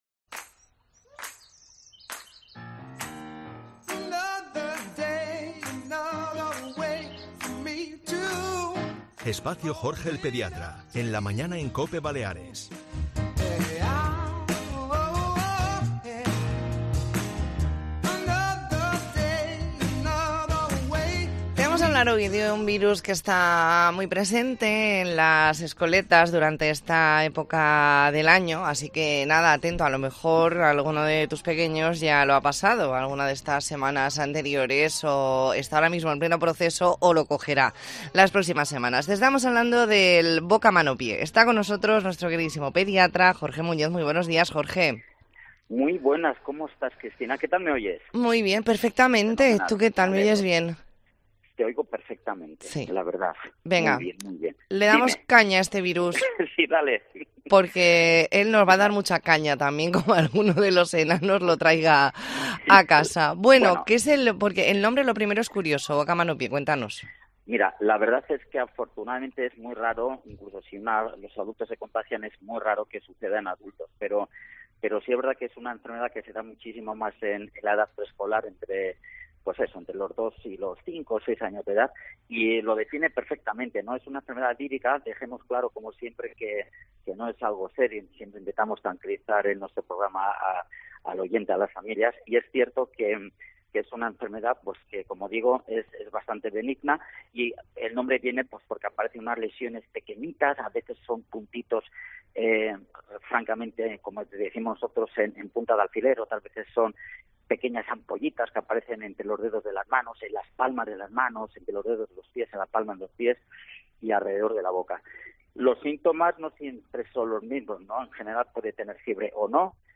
Hoy nos explica con detalle el virus del boca-mano-pie. Entrevista en La Mañana en COPE Más Mallorca, martes 21 de noviembre de 2023.